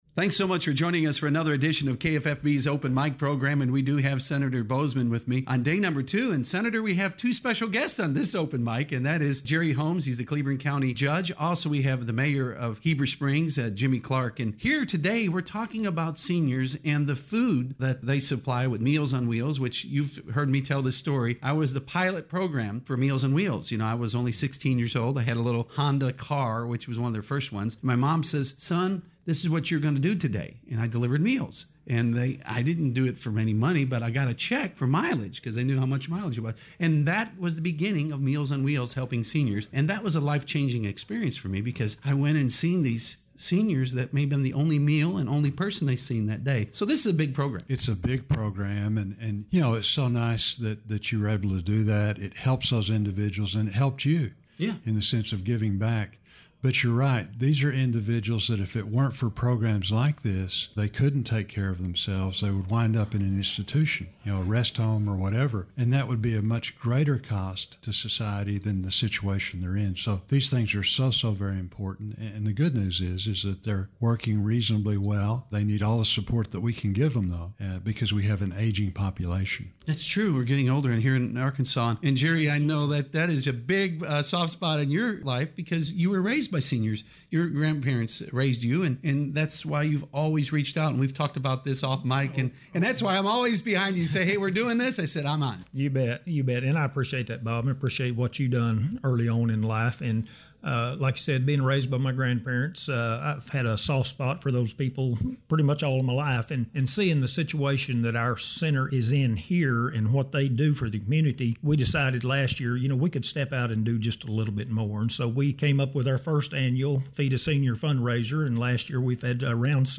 U.S. Senator John Boozman, Cleburne County Judge Jerry Holmes, Heber Springs Mayor Jimmy Clark on KFFB’s Open Mic as they discuss an upcoming fundraiser for Cleburne County Aging Program, and Small Business Administration.